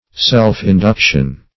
Self-induction \Self`-in*duc"tion\, n. (Elec.)